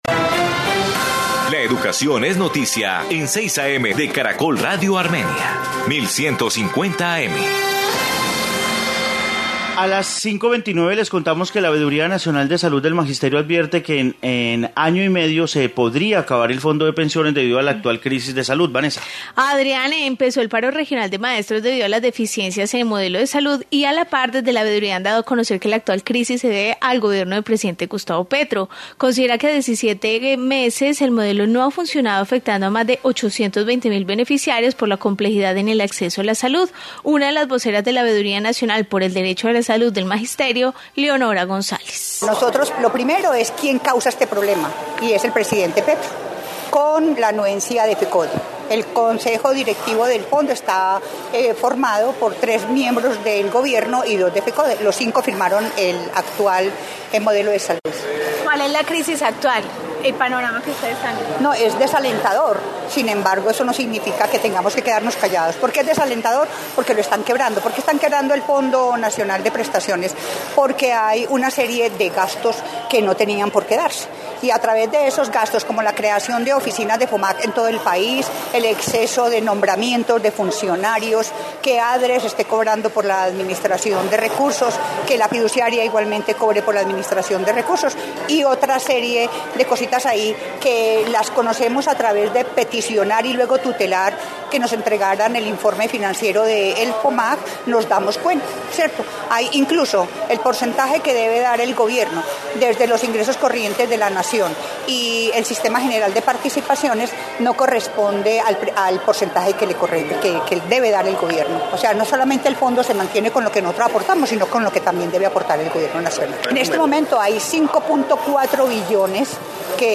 Informe veeduría nacional.